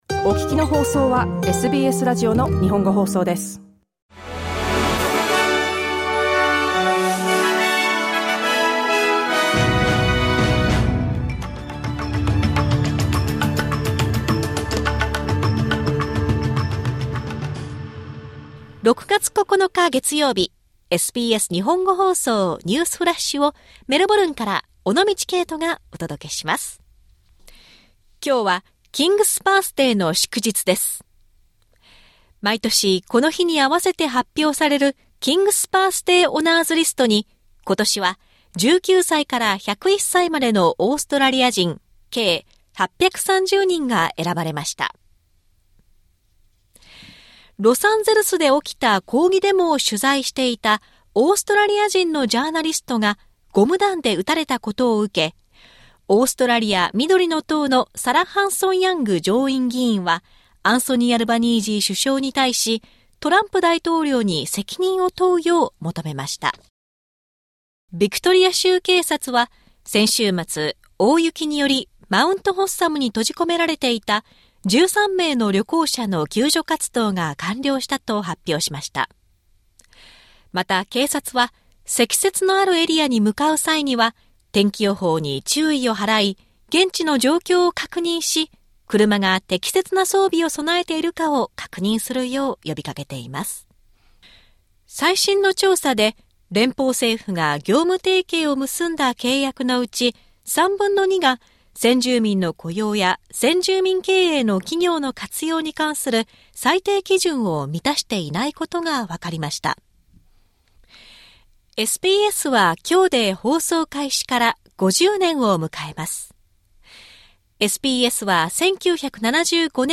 SBS日本語放送ニュースフラッシュ 6月9日 月曜日